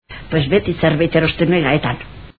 Dialectos – Mediateka
26-El diptongo ai ha evolucionado muchas veces a ei :